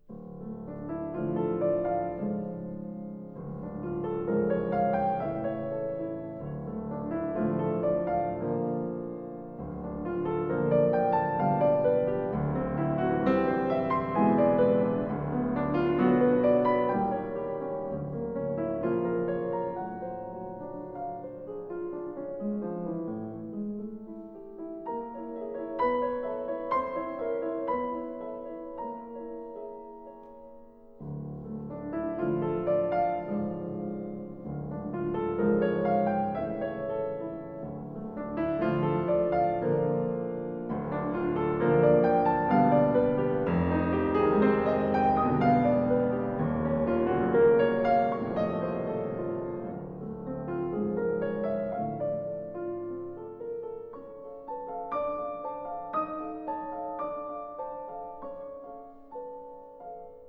I have selected for performance in three recitals a collection